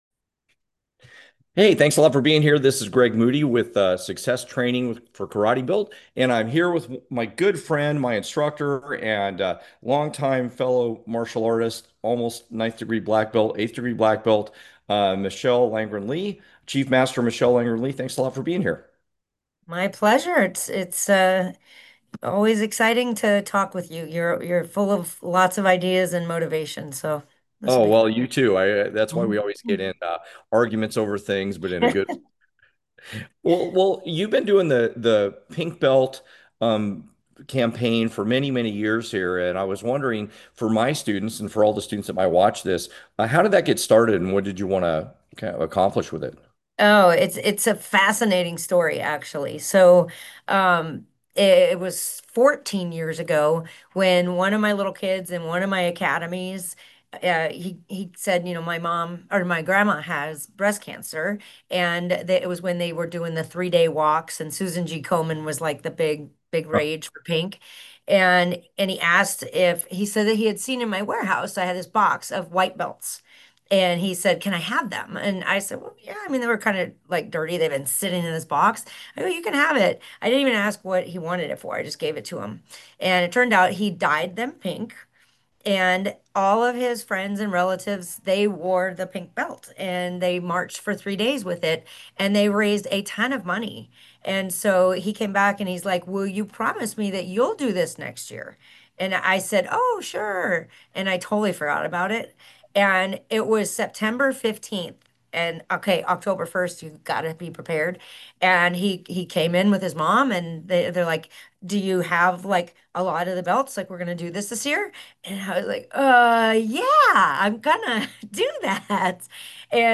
You’ll also learn how the Dignity Kids nonprofit helps bring confidence and respect programs into schools, teaching kids to be black belts in life — not just in class. A powerful conversation about service, leadership, and what it really means to live the martial arts way — with heart.